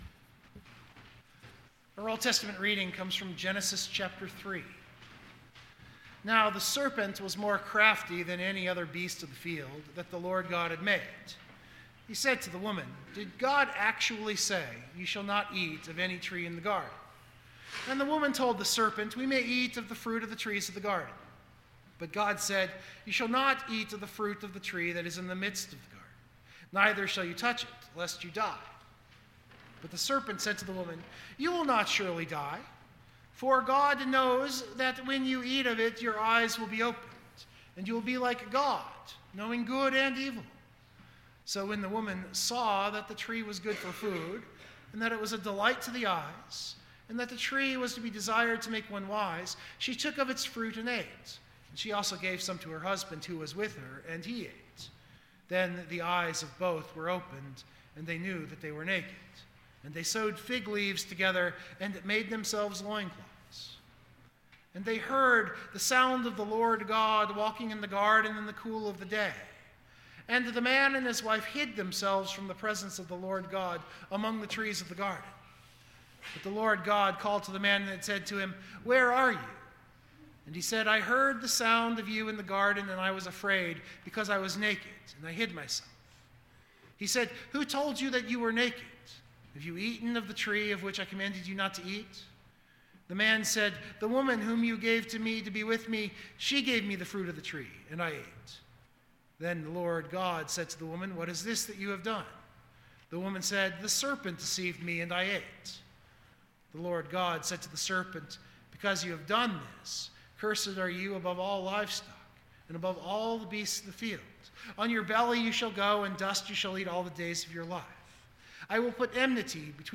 Biblical Text: Matthew 4:1-11 Full Sermon Draft